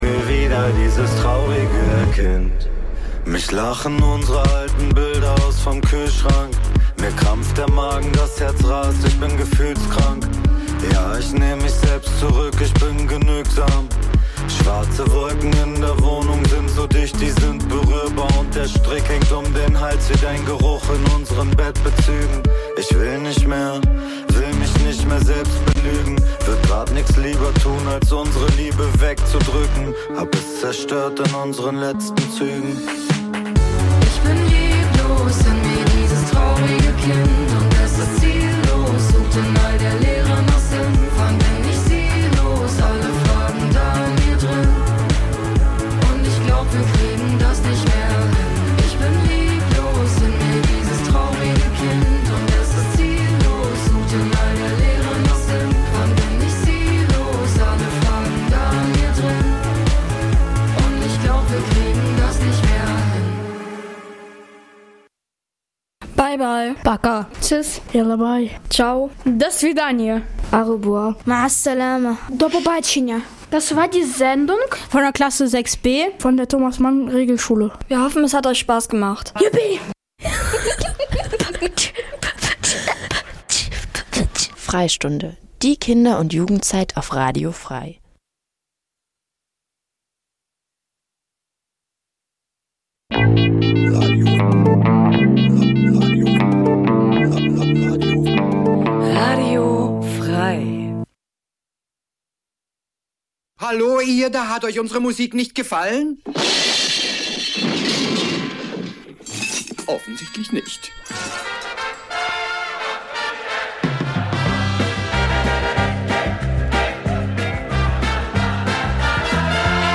Trotzdem lassen wir es uns nicht nehmen immer wieder sonntags ein paar ganz besondere Perlen unserer Schallplattensammlung einer m�den aber durchaus interessierten H�rer-schaft zu pr�sentieren. Hierbei handelt es sich ausschlie�lich um leicht bek�mmliche Musikst�cke aus dem Be-reich Jazz, Soul, Funk, Soundtracks, Beat, French Pop u.s.w. Ganz nebenbei geben wir dem H�rer Informationen zu den gespielten Musikst�cken und vermitteln ihnen wertvolle Tips zu Bew�ltigung des
Easy Listening Dein Browser kann kein HTML5-Audio.